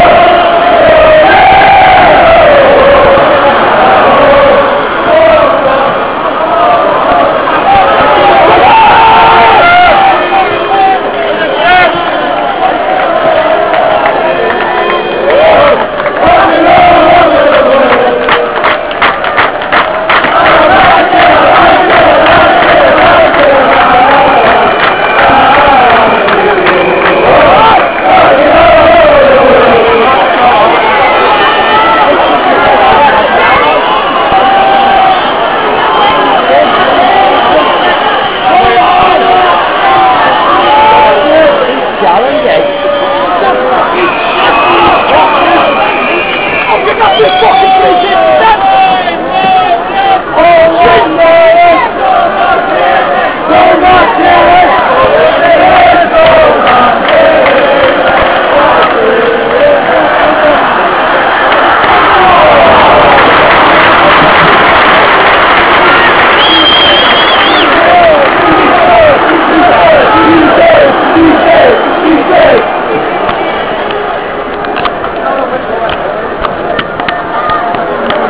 THEME > SUPPORTERS + chants de supporters enregistrés dans les tribunes (fichier mp3